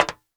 METAL 1B.WAV